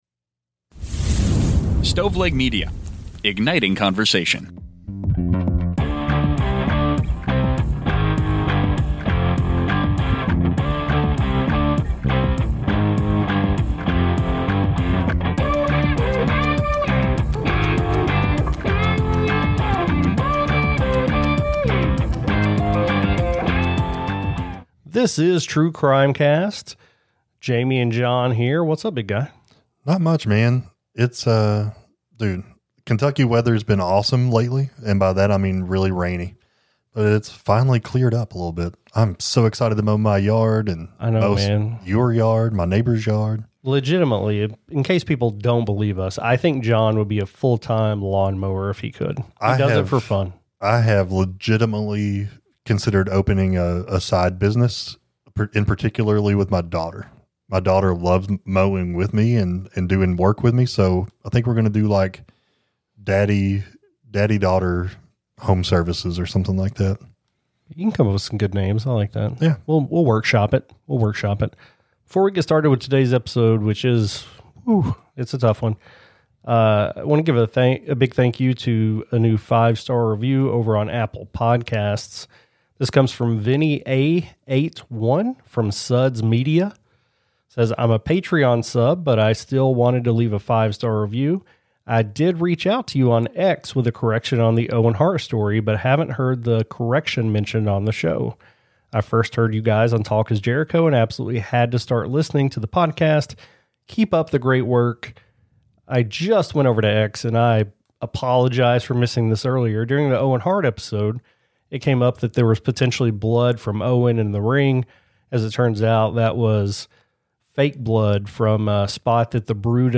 Society & Culture, Personal Journals, Documentary, True Crime